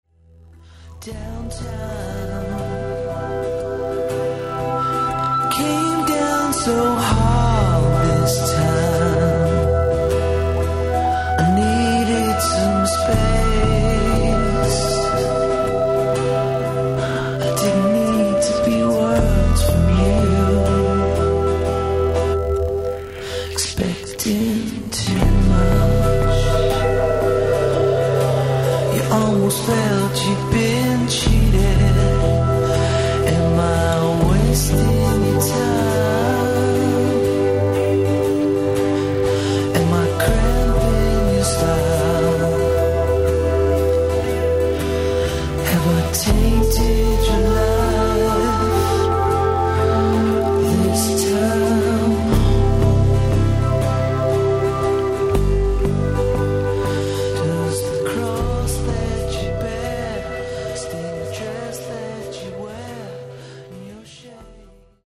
an acoustic guitar and PC
lo-fi demos